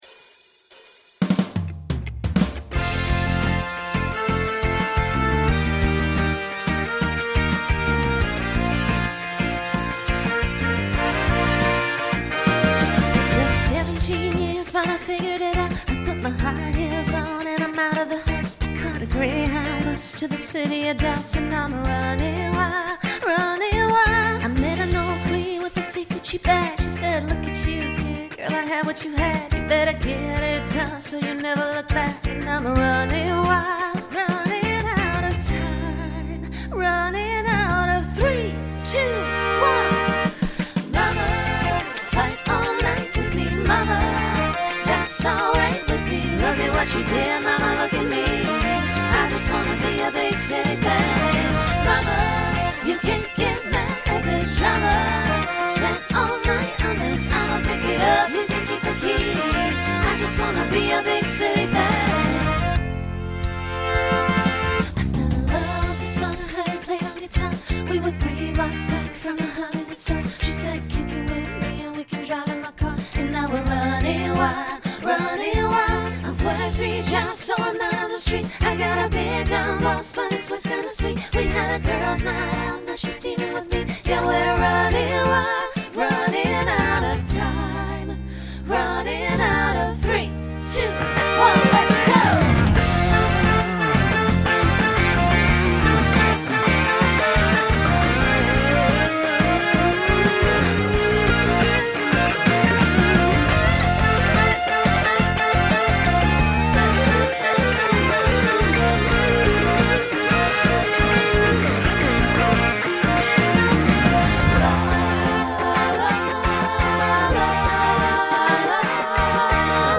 Genre Rock Instrumental combo
Solo Feature , Transition Voicing SSA